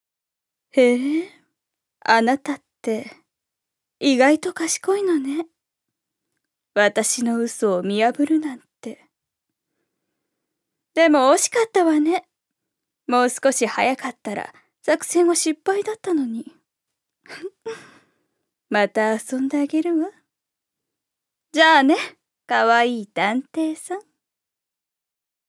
預かり：女性
セリフ４